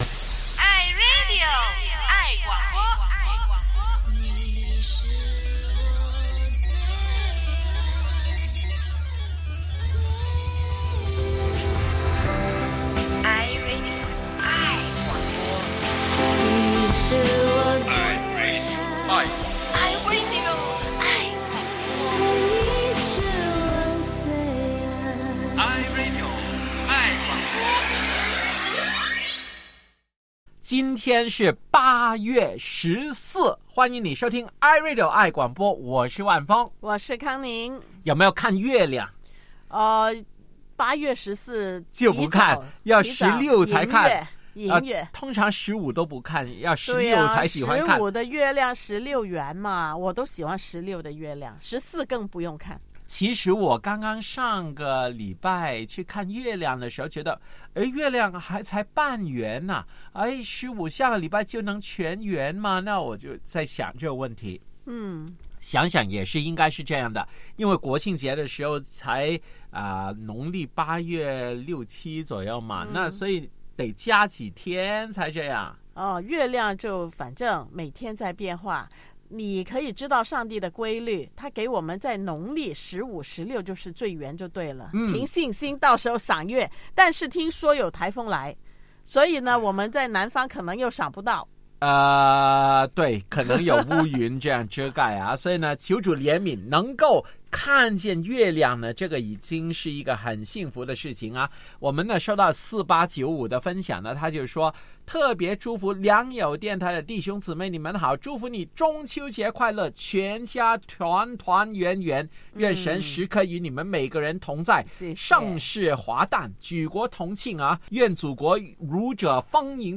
《献上今天》听众歌声陪你过中秋！让我们一起唱出美好的假期，天上人间一同相聚！